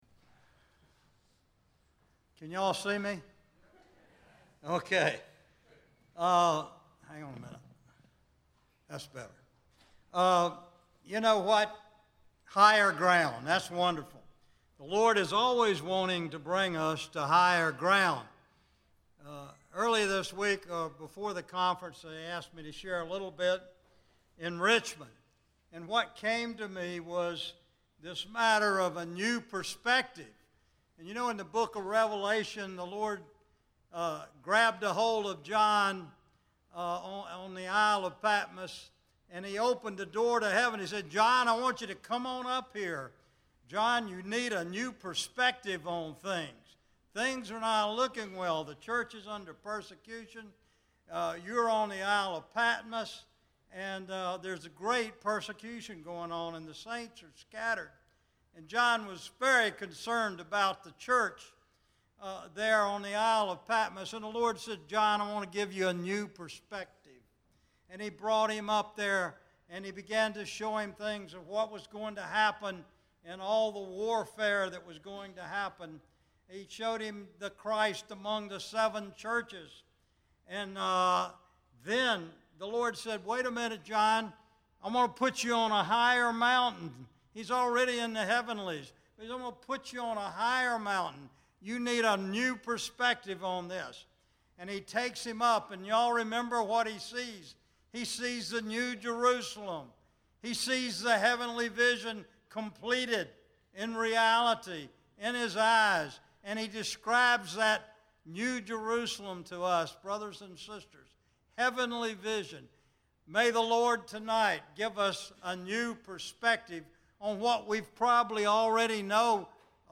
Christian Family Conference
Message